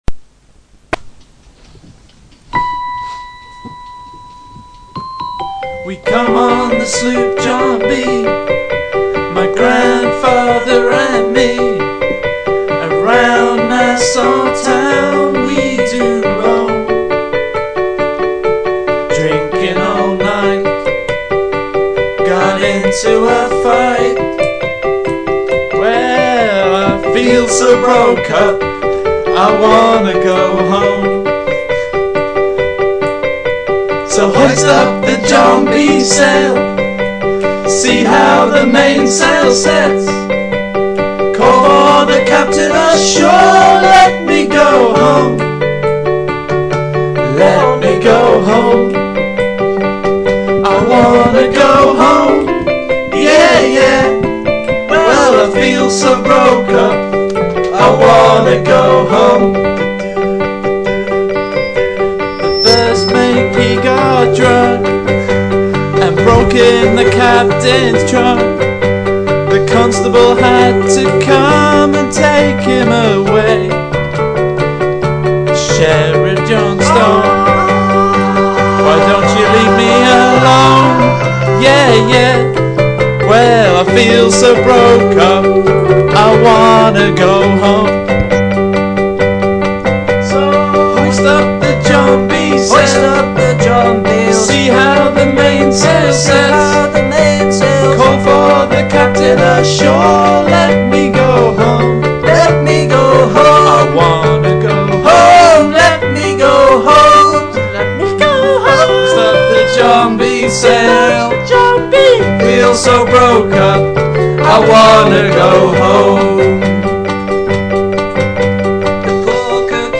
originally-intended vocal parts